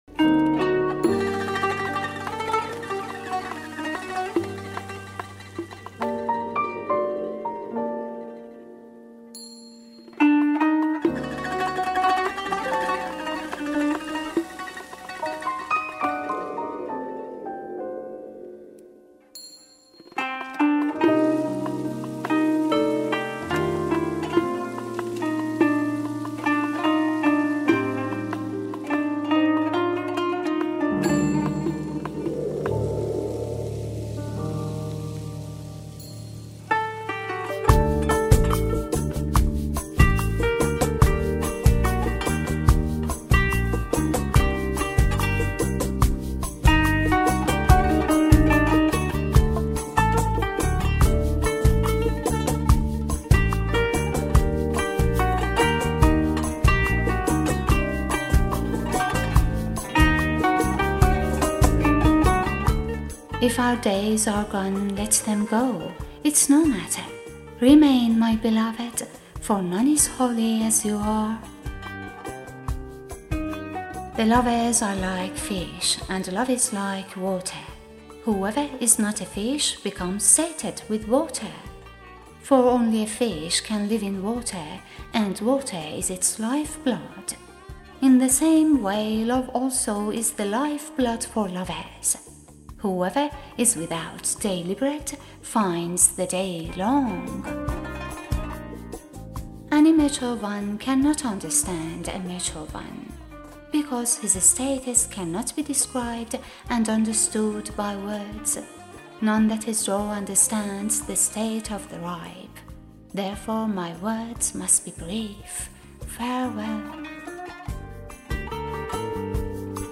Narrator and Producer:
Music by: